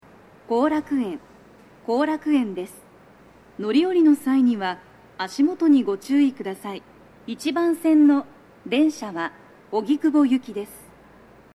足元注意喚起放送が付帯されており、粘りが必要です。
到着放送2
JVC横長型での収録です。